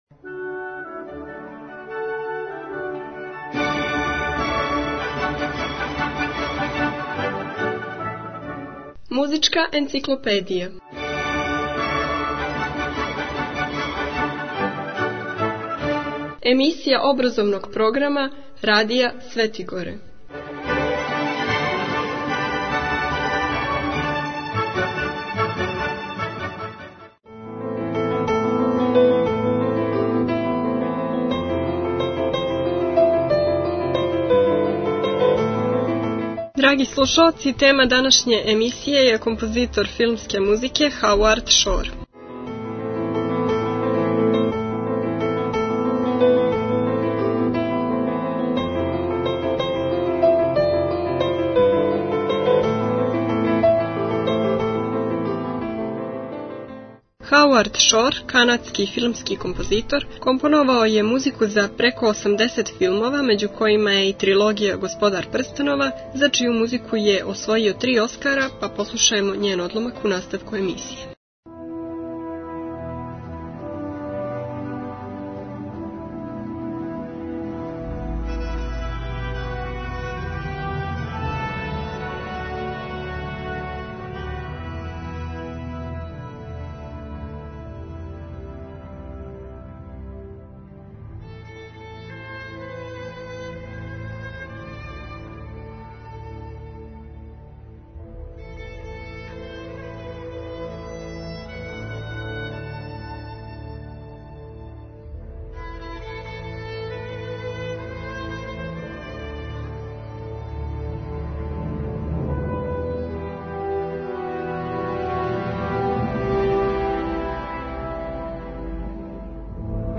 можете чути музику филмског композитора